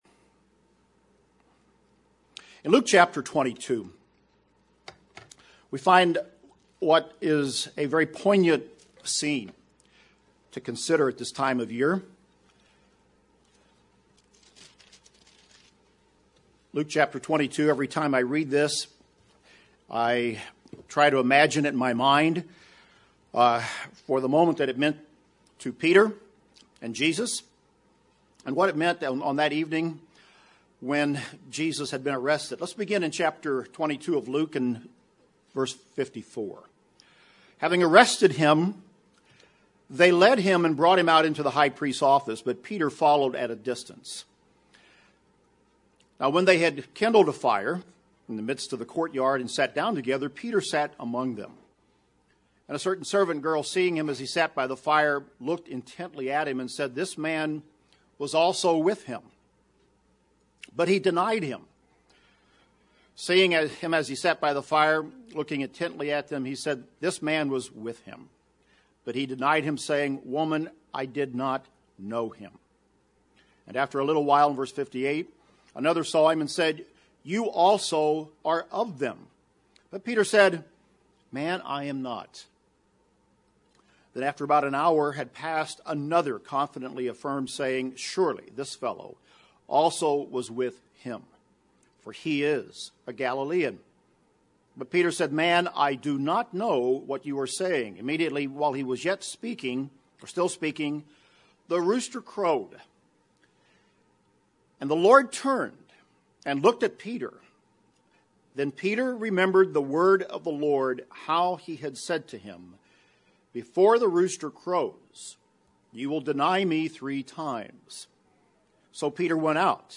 UCG Sermon Jesus Christ Passover self examination Transcript This transcript was generated by AI and may contain errors.